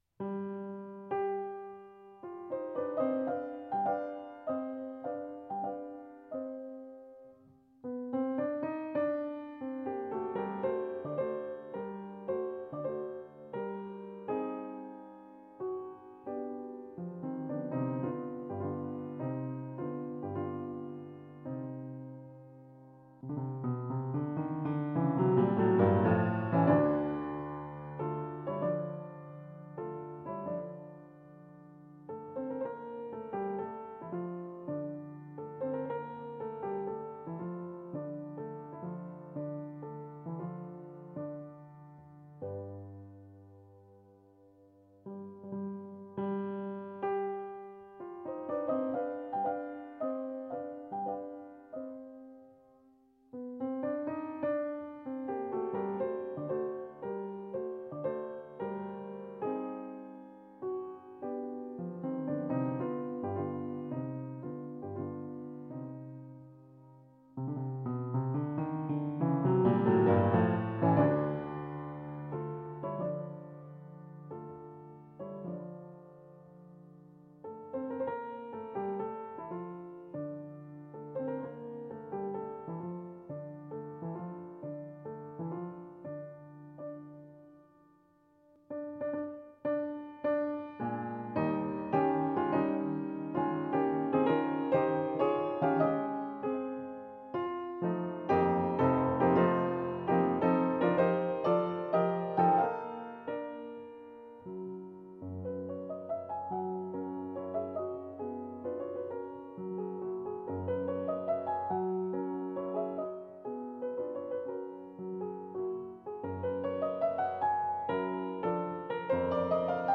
Classical (View more Classical Piano Music)